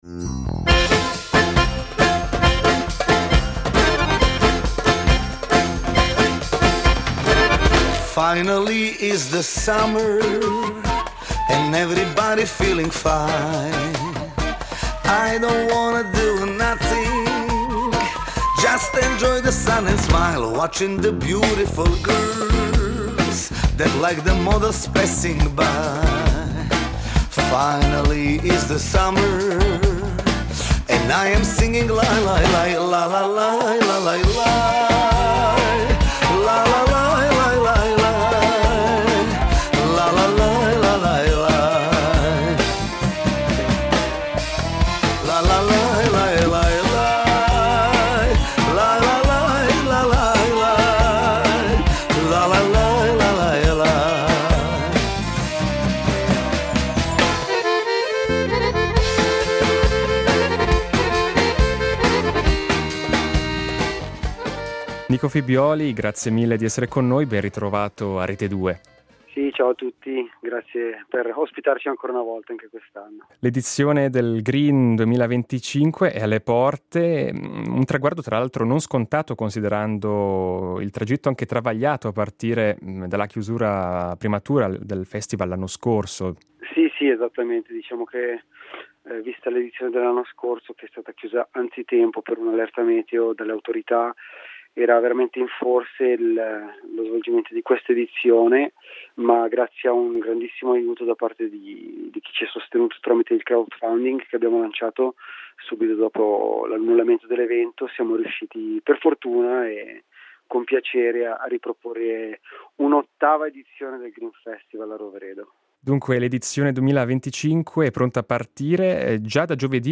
Musicalbox Grin Festival 2025 Incontro